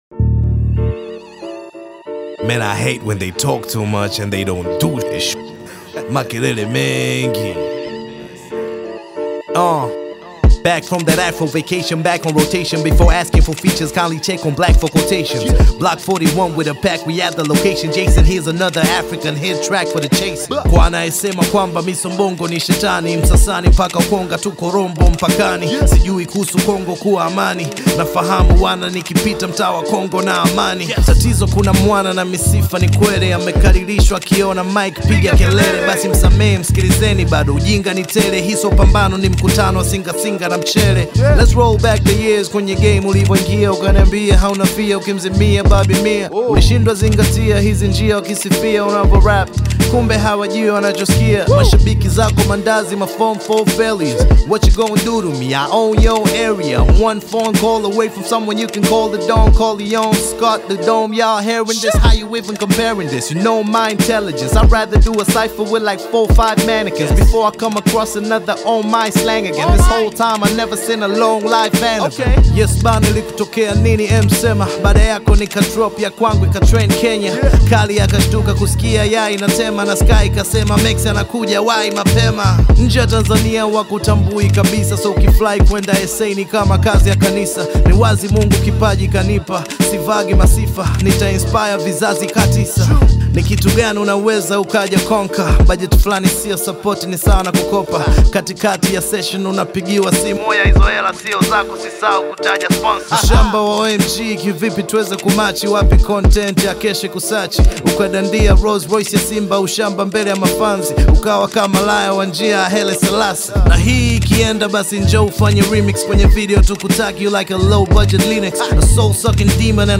Hapa Hiphop ndiyo nyumbani mkuu.